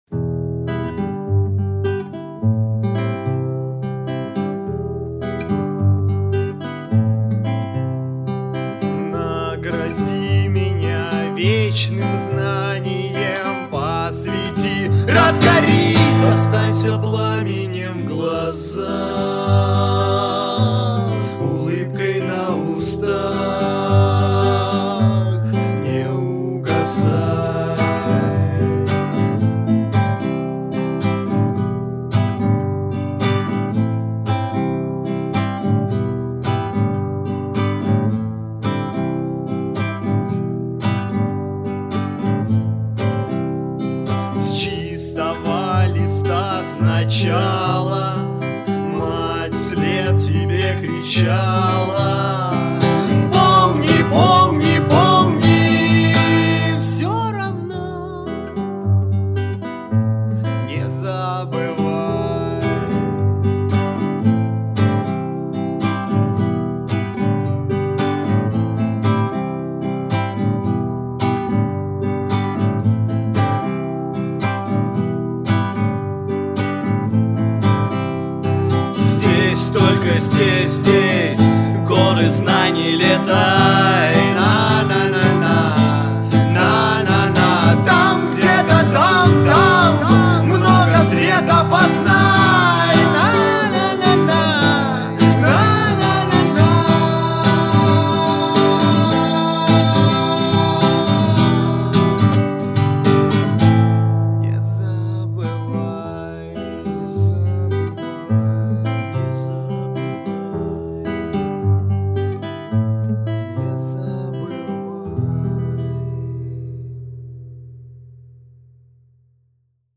• Жанр: Комедия
Приготовлено для посвящения СФ МГПУ 2006 год